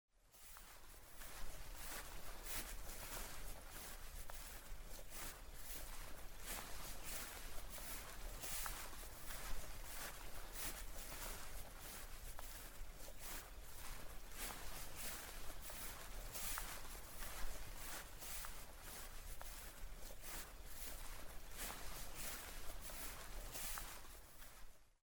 WALK THROUGH FORESTWALK THROUGH FOREST
Ambient sound effects
Walk_Through_ForestWalk_Through_Forest.mp3